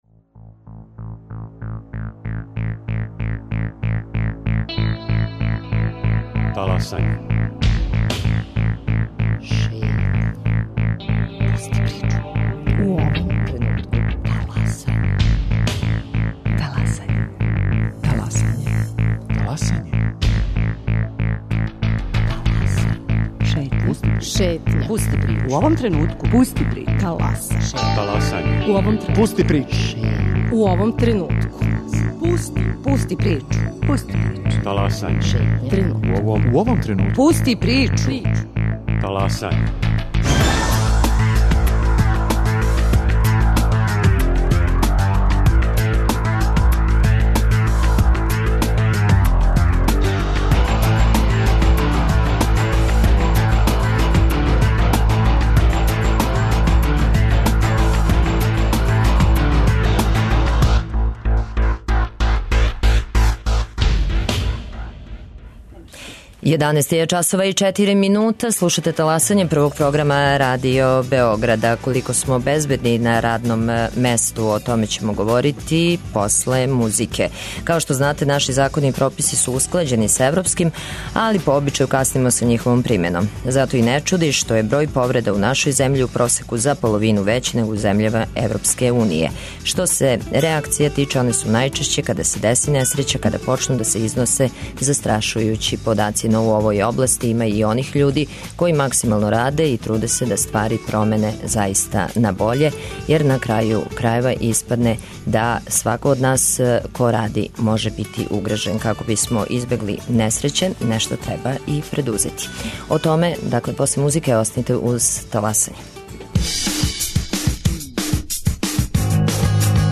Гости Таласања: Вера Божић Трефалт, директорка Управе за безбедност и здравље на раду Министарства рада и социјалне политике и Предраг Перуничић, директор Републичког инспектората за рад.